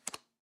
single_deal.m4a